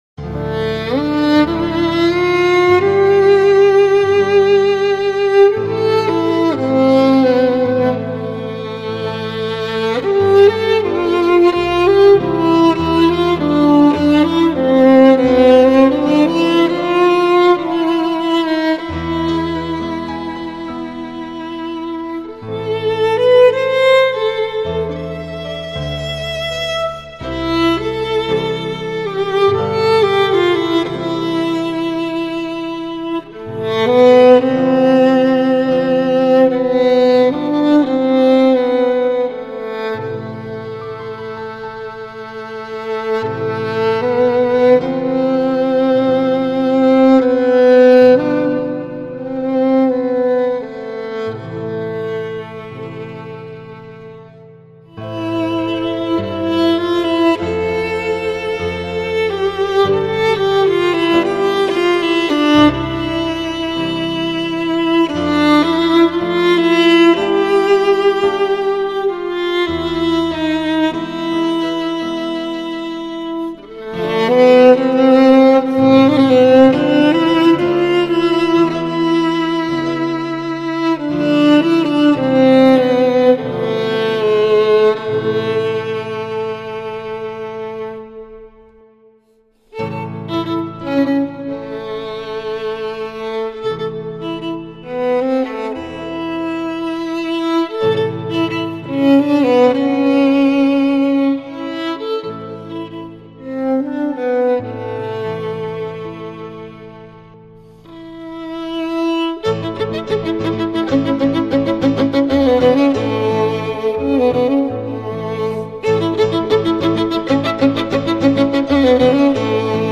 Versione strumentale
violino
Si tratta di un pezzo strumentale, senza testo.